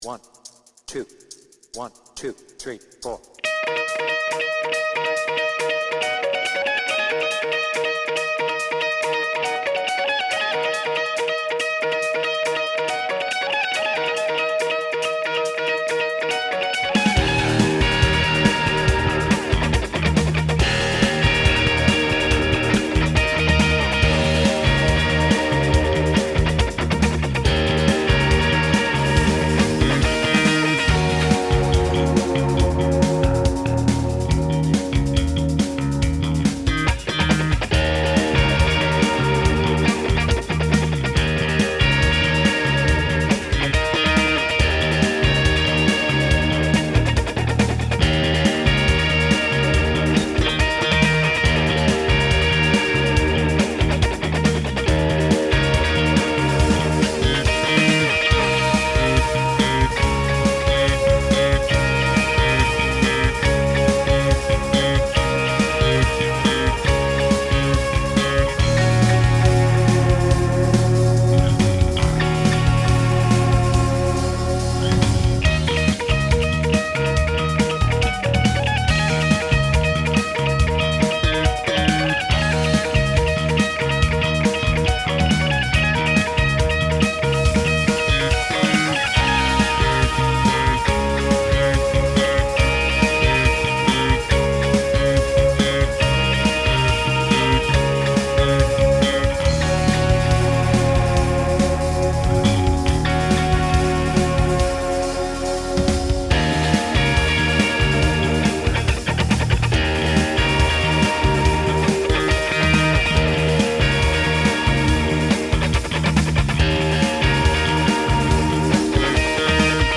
BPM : 140
Tuning : Eb
Without vocals